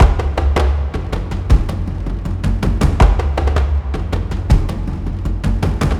Sound_10709_WorldDrumsC.ogg